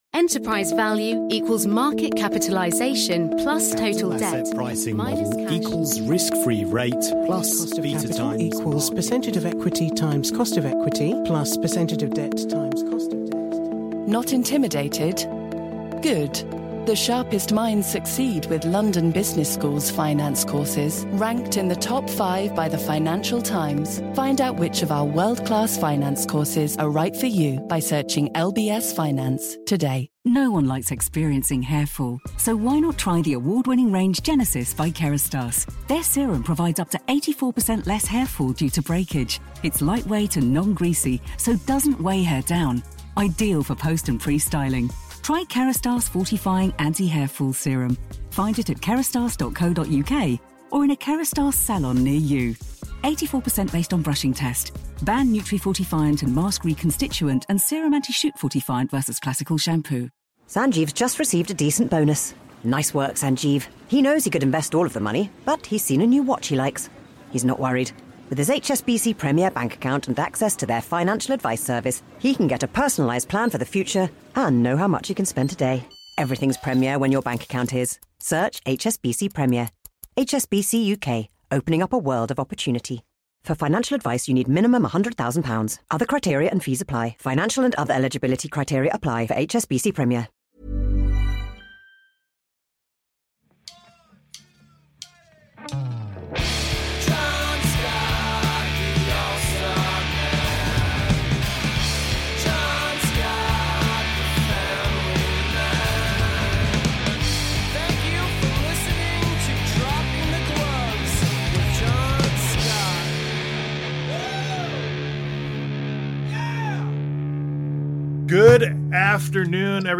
Interview with Tage Thompson of the Buffalo Sabres